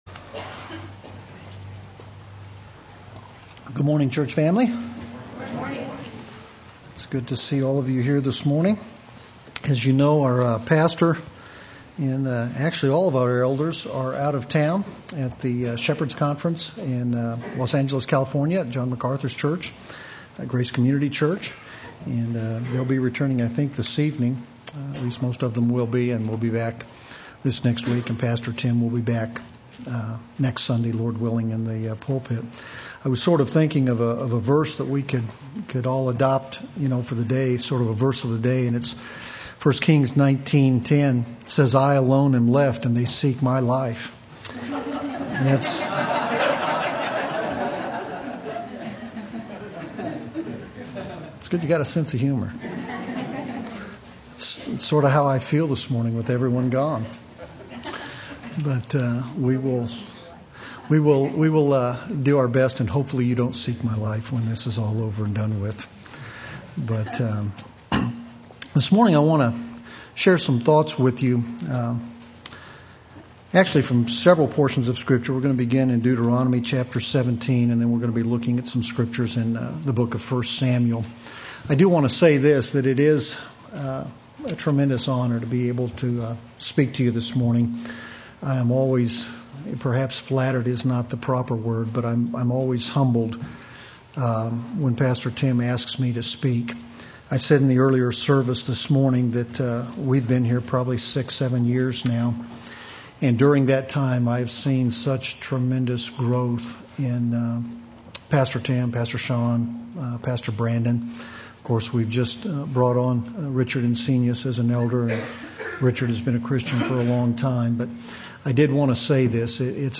Play Sermon Get HCF Teaching Automatically.